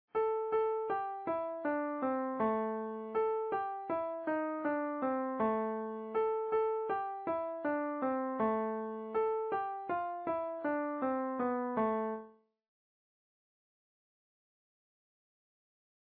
A minor scale